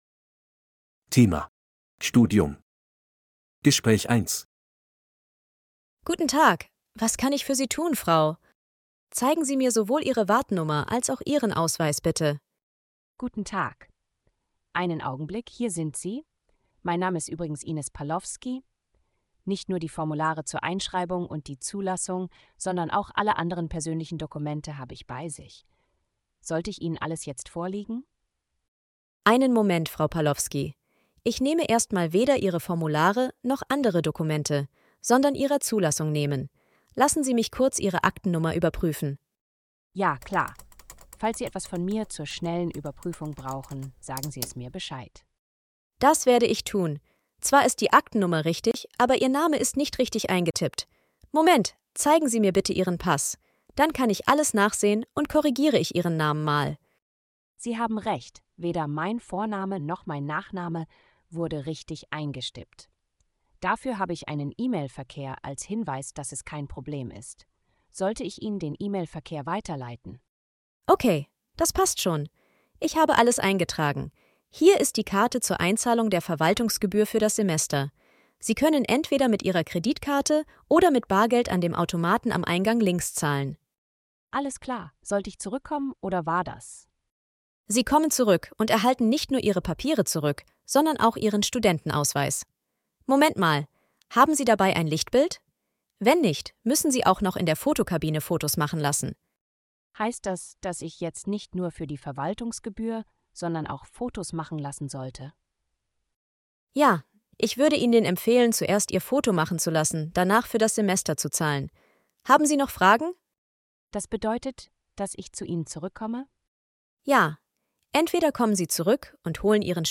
Bài nghe hội thoại 1:
B1-Registeruebung-5-Studium-Gespraech-1.mp3